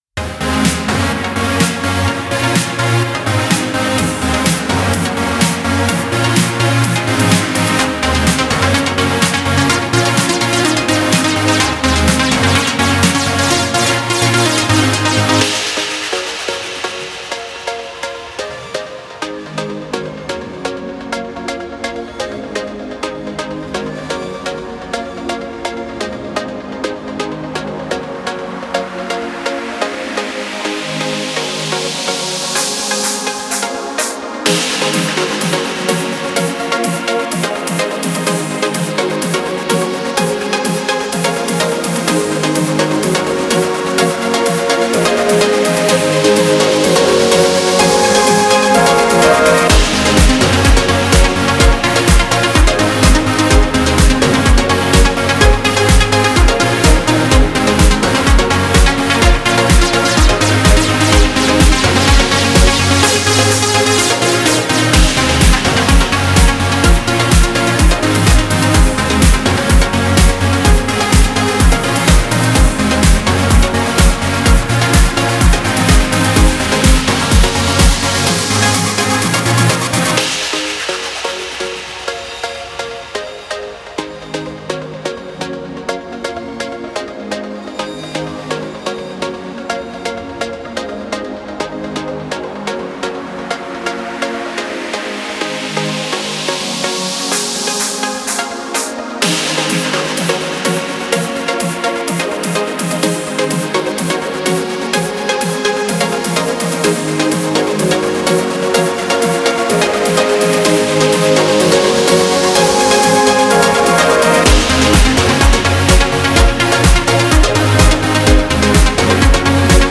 Trance